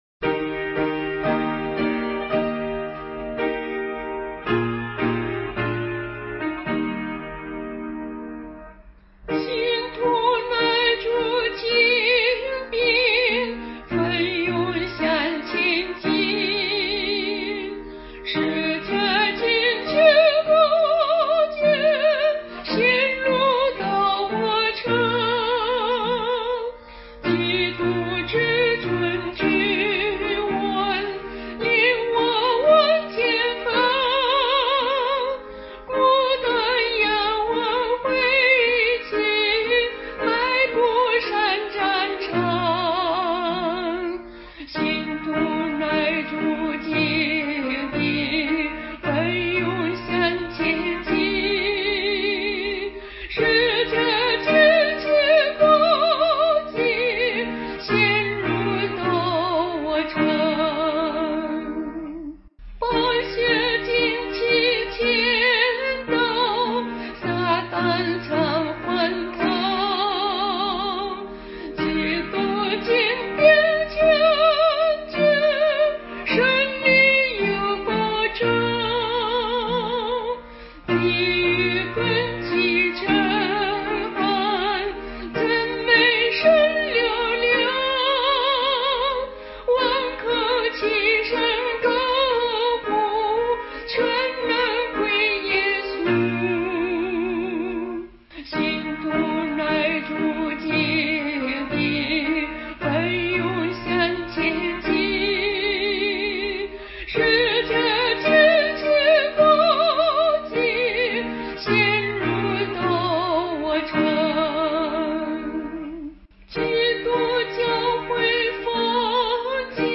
原唱